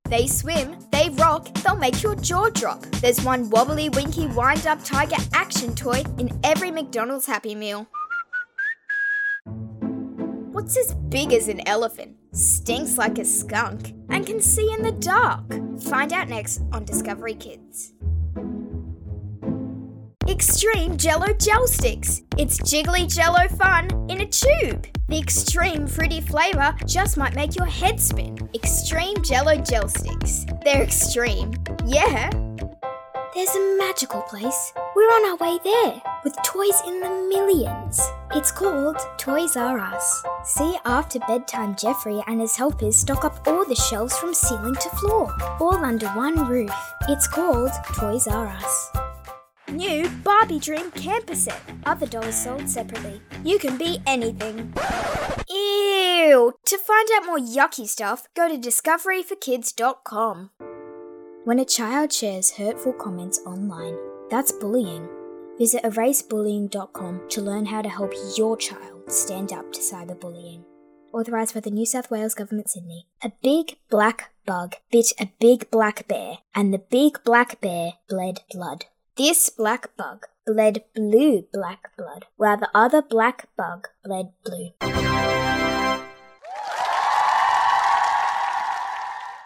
Professionnal, soft, smile, friendly according to the project. I do specialize in e-learning modules, corporate and products presentation videos or events and IVR voice over projects but I will...
French (Canadian) Adult (30-50)